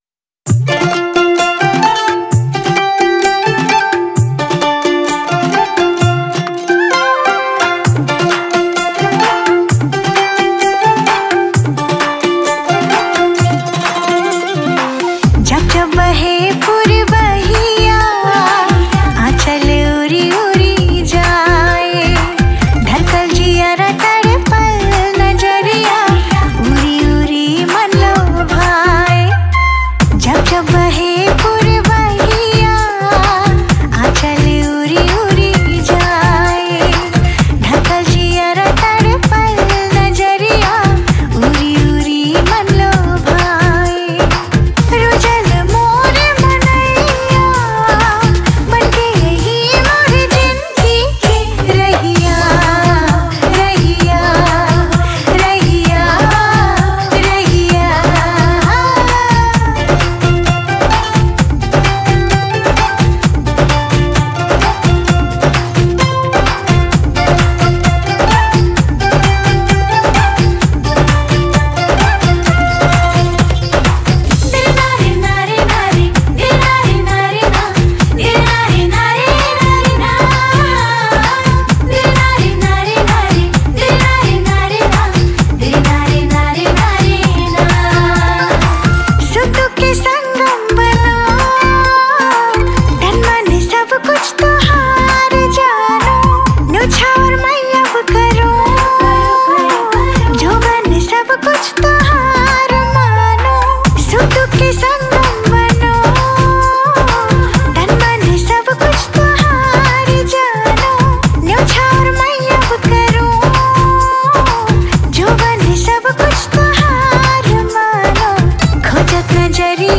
Tharu Romantic Song